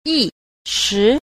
9. 意識 – yìshí – ý thức
yi_shi.mp3